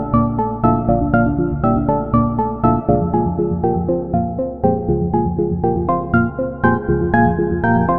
标签： 120 bpm Chill Out Loops Piano Loops 1.35 MB wav Key : A
声道立体声